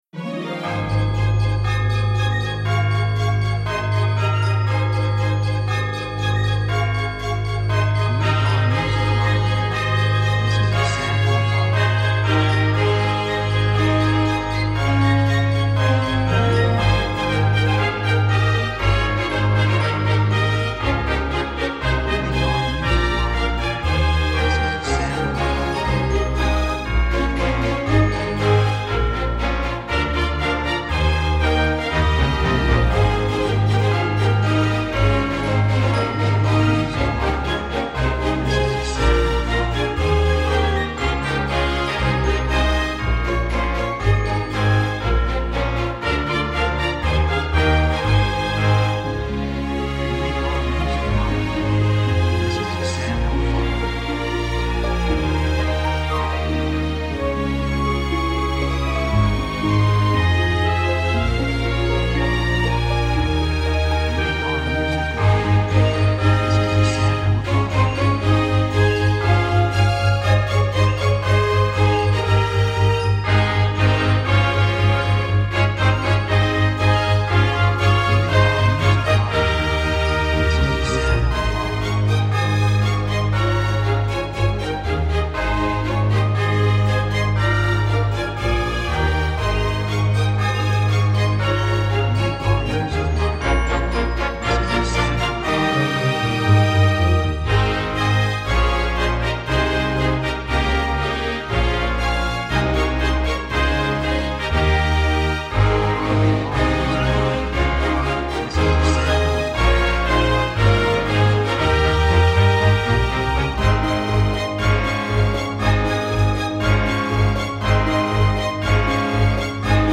雰囲気幸せ, 高揚感, 喜び, 感情的
曲調ポジティブ
楽器ベル, ハープ, オーケストラ
サブジャンルクリスマス, オーケストラ
テンポやや速い